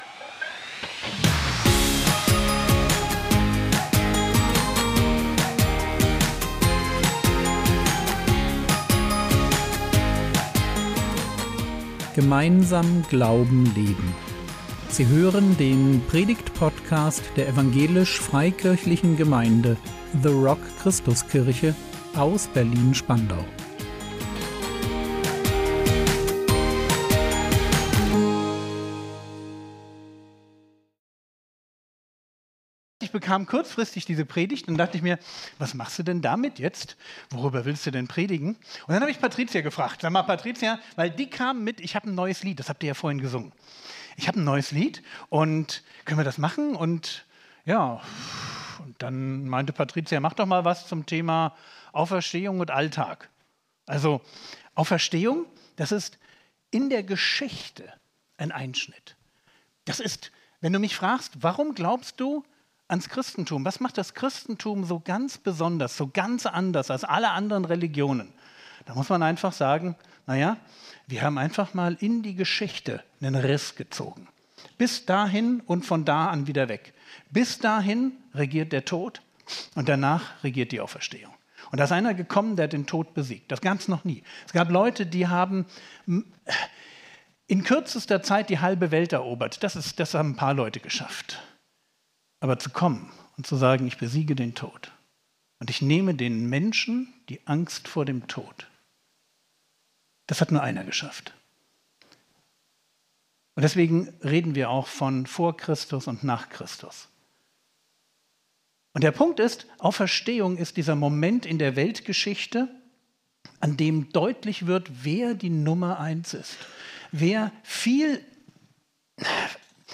Auferstehung im Alltag | 05.04.2026 ~ Predigt Podcast der EFG The Rock Christuskirche Berlin Podcast